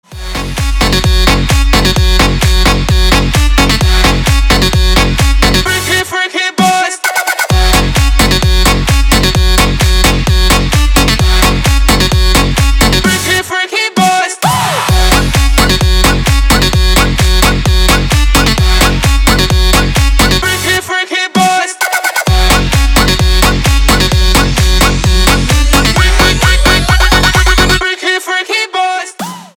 • Качество: 320, Stereo
мужской голос
громкие
dance
Electronic
электронная музыка
club
Big Room